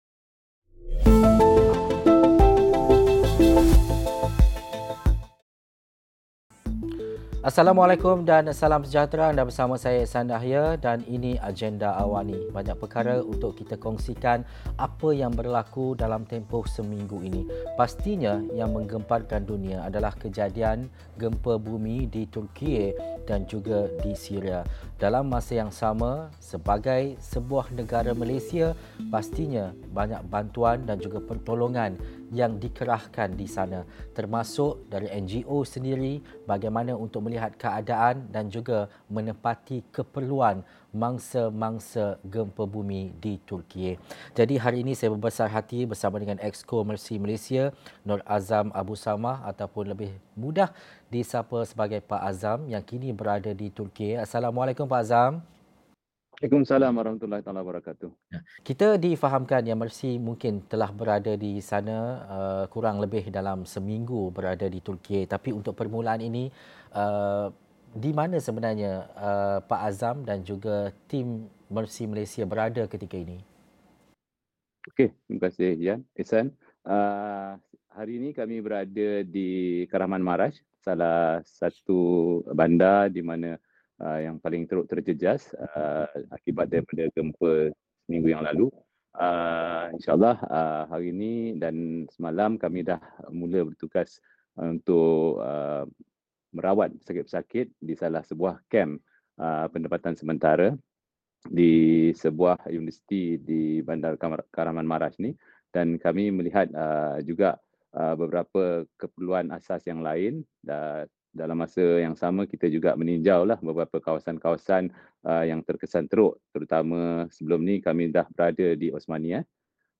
Diskusi 9 malam.